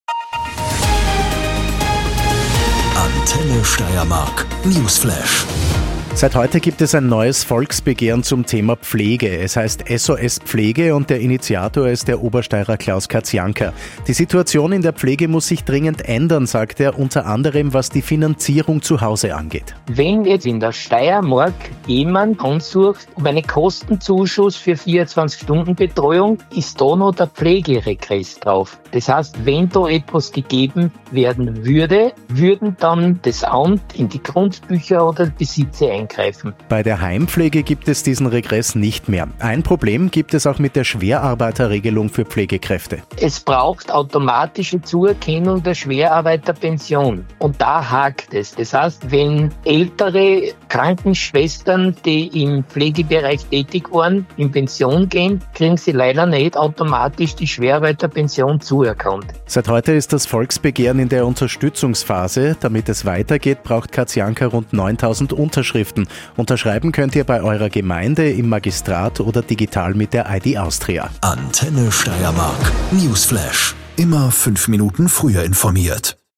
Newsflash – Volksbegehren SOS Pflege